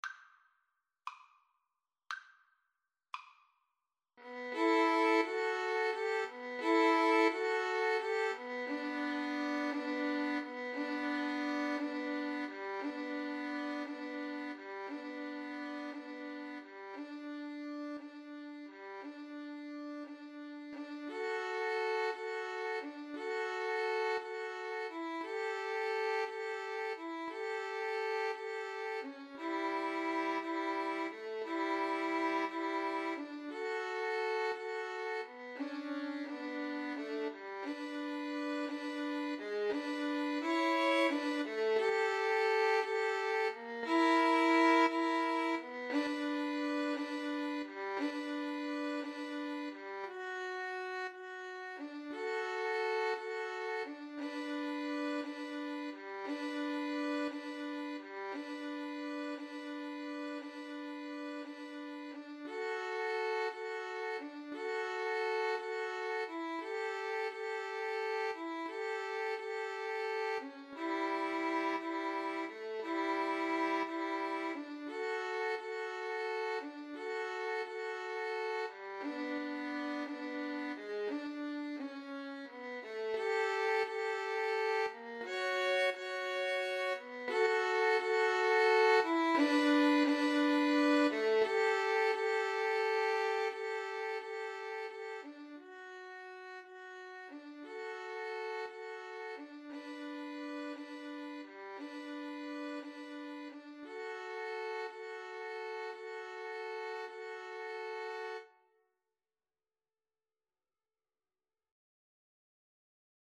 6/8 (View more 6/8 Music)
.=58 Andante con moto (View more music marked Andante con moto)
B minor (Sounding Pitch) (View more B minor Music for Violin Duet )
Violin Duet  (View more Intermediate Violin Duet Music)
Classical (View more Classical Violin Duet Music)